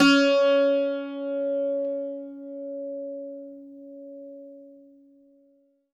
52-str06-bouz-c#3.wav